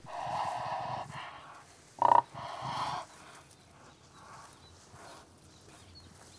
When their territory is about to be invaded or some danger is threatening, Whooping Cranes make a comparatively soft “
Whooping Crane